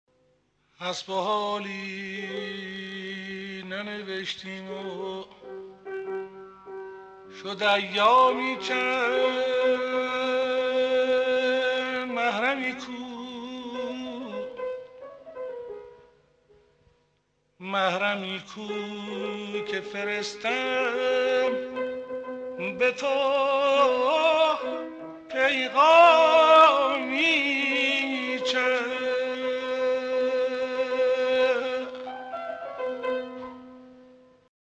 اشاره به حصار در درآمد چهارگاه را با صدای غلامحسین بنان بشنوید.